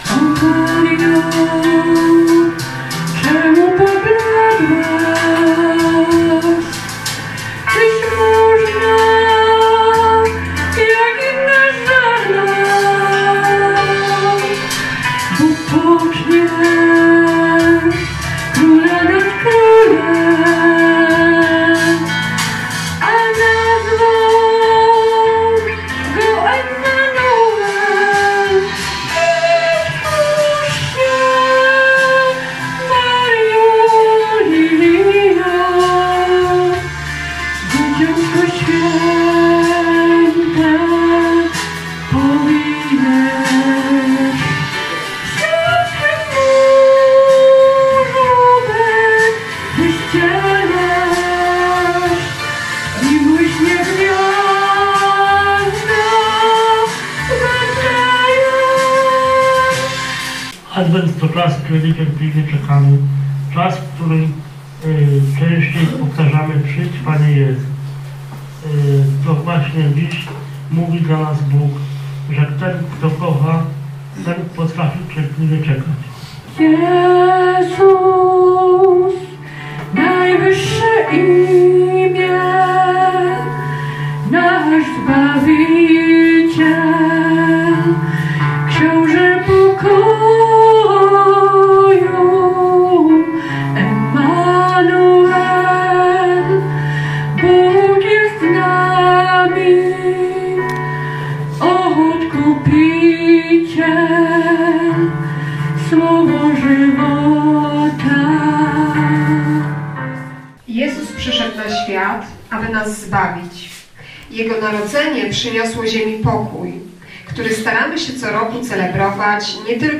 Poprzedza je program artystyczny, ściśle odwołujący się do świątecznego czasu.
Program został nagrodzony gorącymi i szczerymi brawami.
Po symbolicznym przełamaniu opłatków i wspólnym zaśpiewaniu kolędy „Wśród nocnej ciszy”, uczestnicy warsztatu, jego pracownicy i goście zasiedli do stołów zastawionych wigilijnymi potrawami.